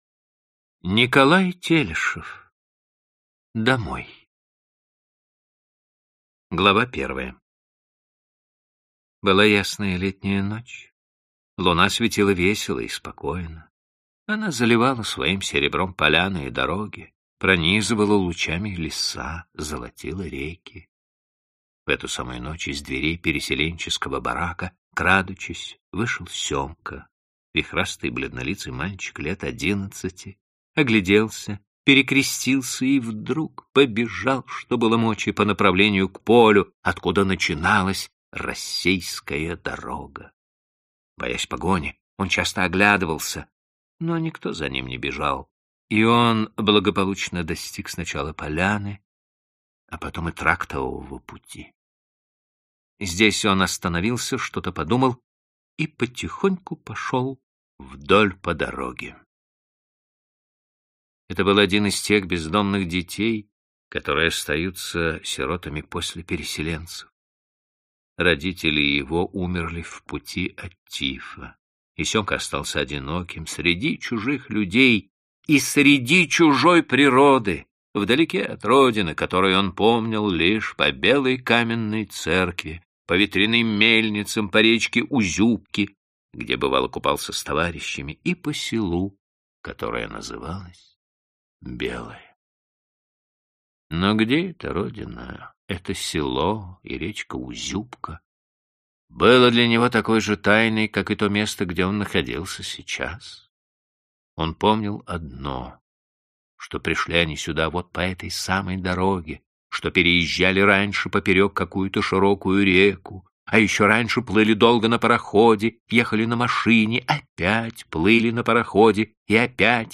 Аудиокнига Домой | Библиотека аудиокниг